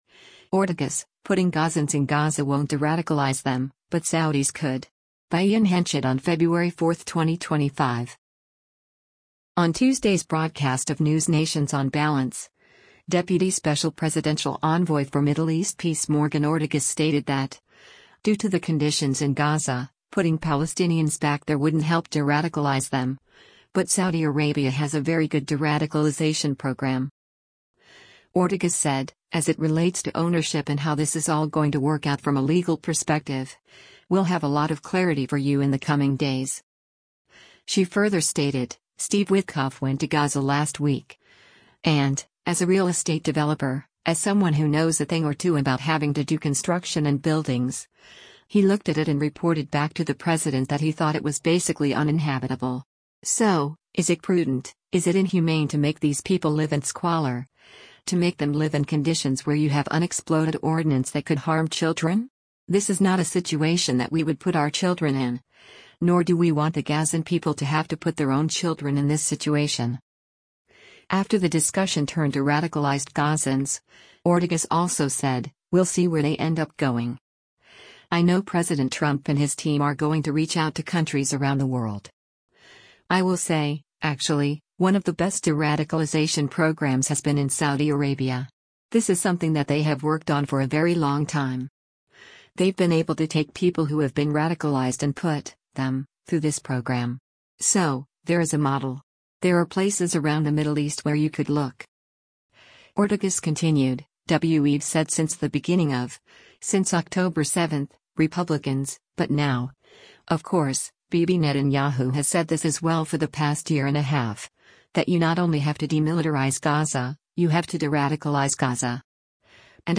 On Tuesday’s broadcast of NewsNation’s “On Balance,” Deputy Special Presidential Envoy for Middle East Peace Morgan Ortagus stated that, due to the conditions in Gaza, putting Palestinians back there wouldn’t help deradicalize them, but Saudi Arabia has a very good deradicalization program.